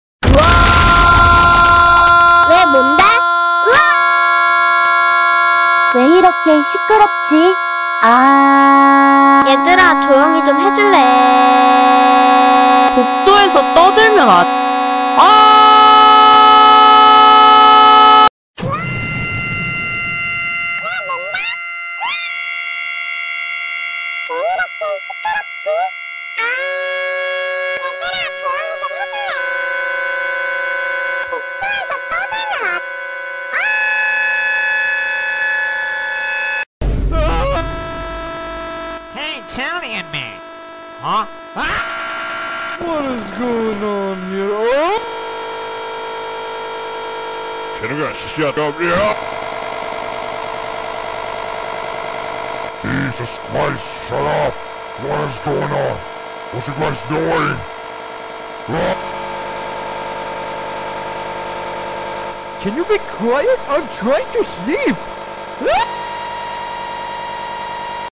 Pizza-Tower-Scream-Meme-But-Sprunki-Incredibox.mp3